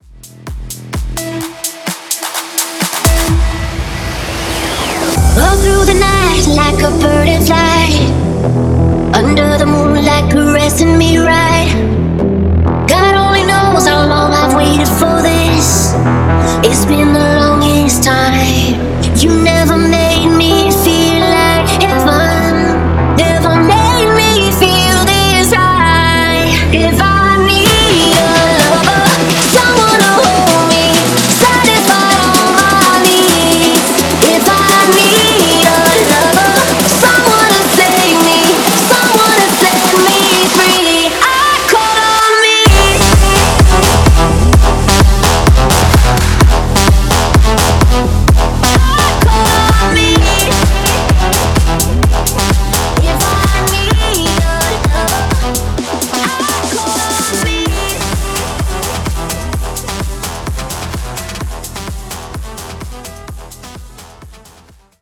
Genres: HIPHOP , TOP40
Dirty BPM: 110 Time